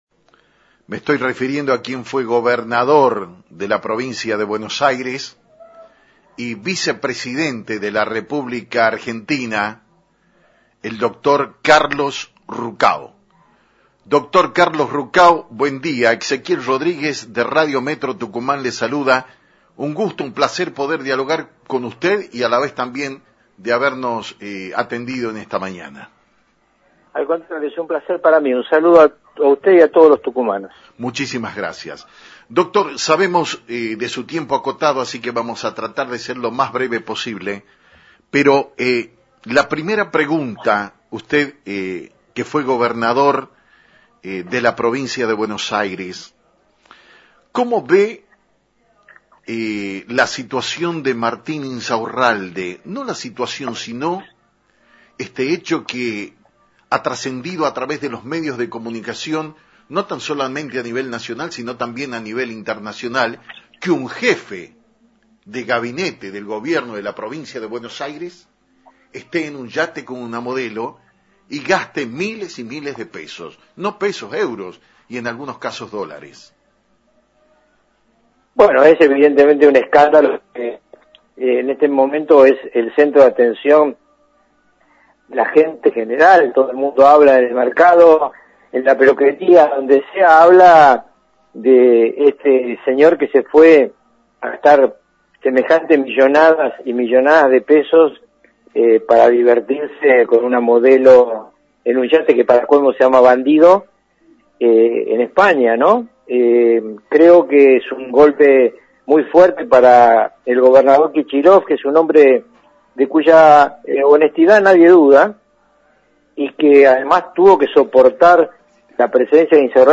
el ex vicepresidente de la Nación, Dr. Carlos Ruckauf, habló sobre lo relacionado al escándalo en el que se viera envuelto el ex jefe de Gabinete de Axel Kicillof, Martín Insaurralde, quien días pasados se fuera de viaje a Marbella en un yate de lujo con una modelo argentina, «Es un gran golpe para Kicillof que es un hombre de quien nadie duda de su honestidad, además tuvo que tenerlo en su gabinete por presión del kirchnerismo; este escándalo se mantendrá en vigencia los próximos días».